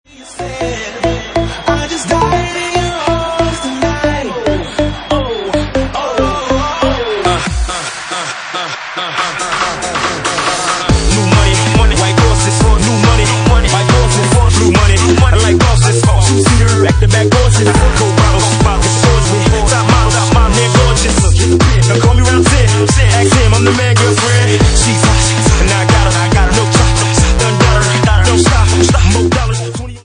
Bassline House at 146 bpm